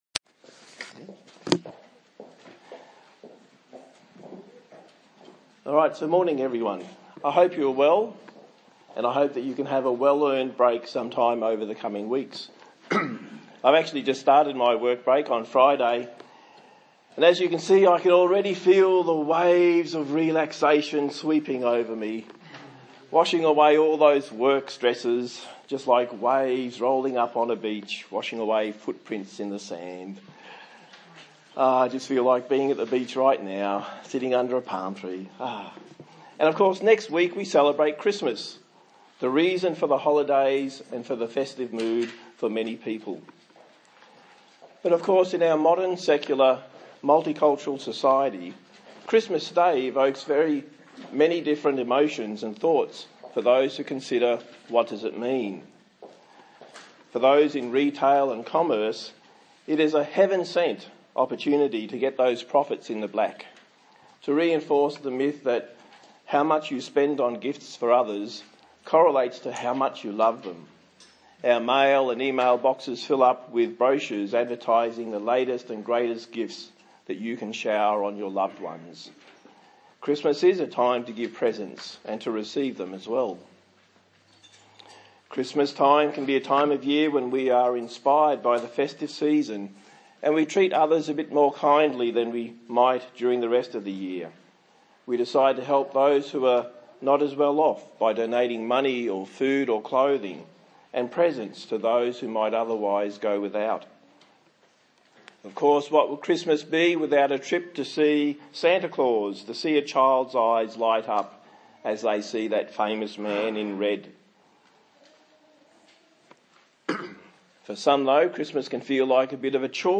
18/12/2016 Christ’s coming into the World Preacher
A sermon on Christmas